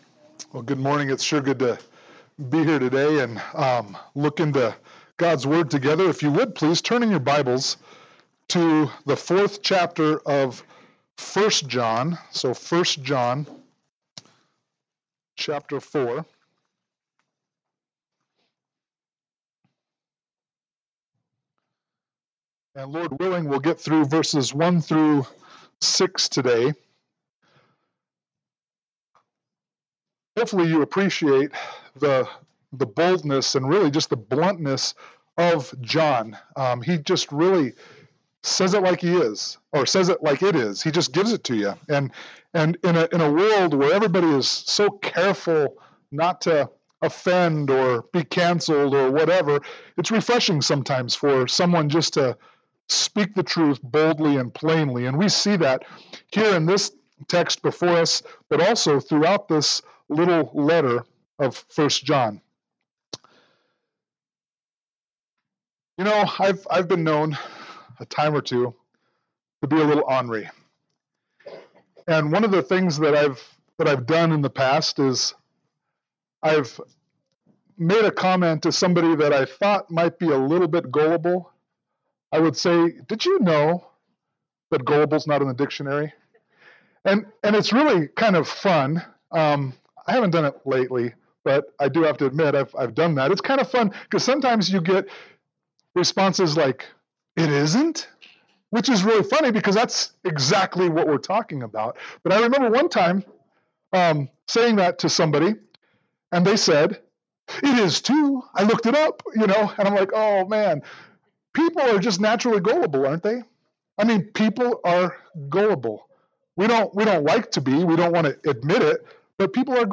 1 John 4:1-6 Service Type: Sunday Morning Worship « 1 John 3:19-24